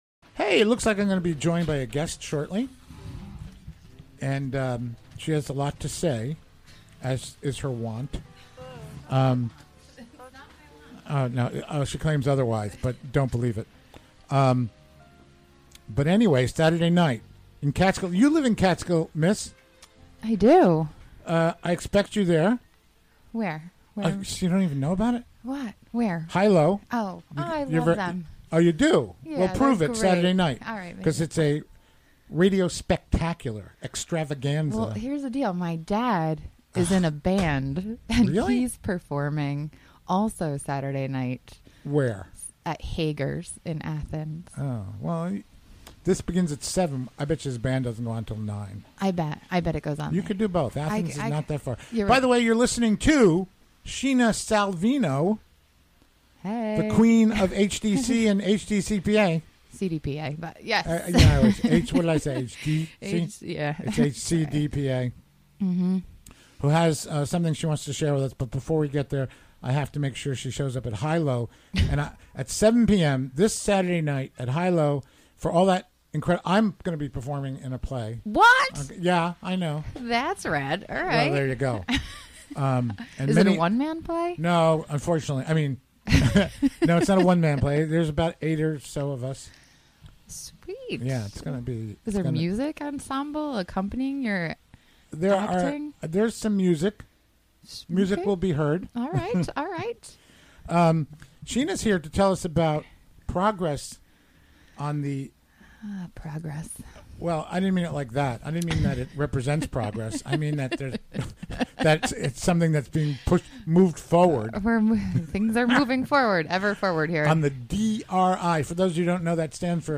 Recorded live during the WGXC Afternoon Show, Thu., Feb. 22.